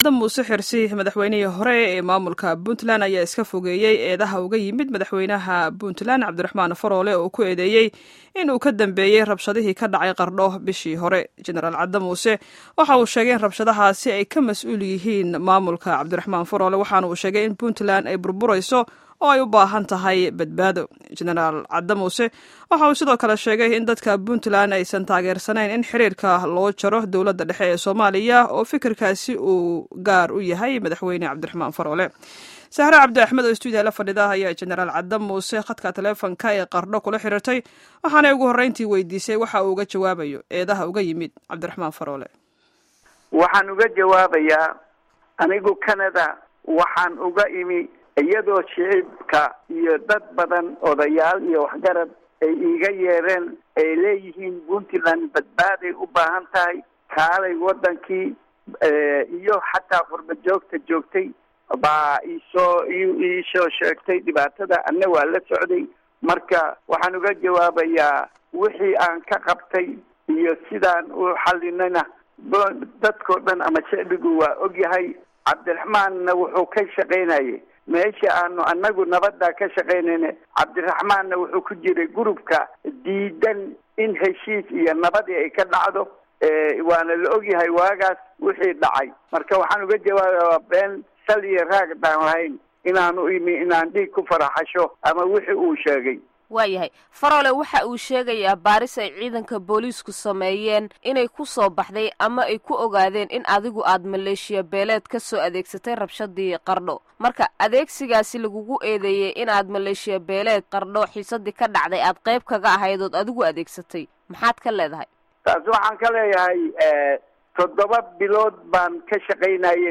Wareysiga Cadde Muuse